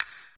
Delayed Reverb Pop.wav